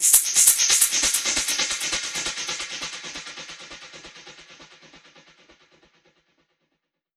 Index of /musicradar/dub-percussion-samples/134bpm
DPFX_PercHit_E_134-07.wav